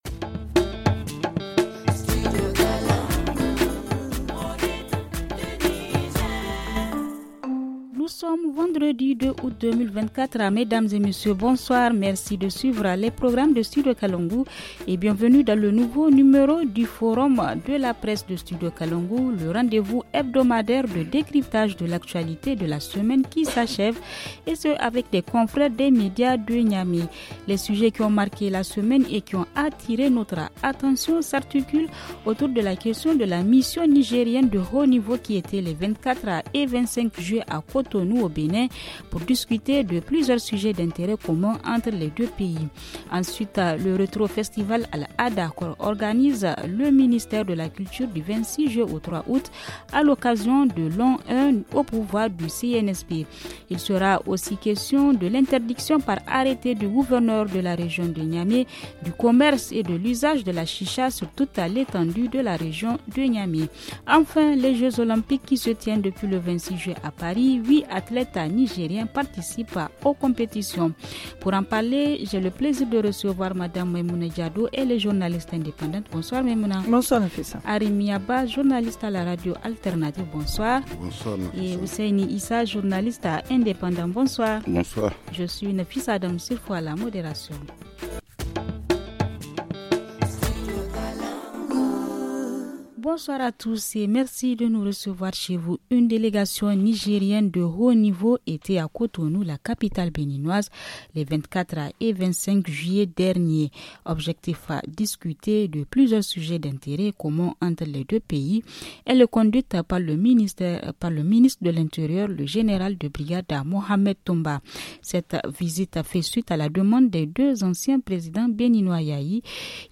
Dans le forum de la presse de cette semaine :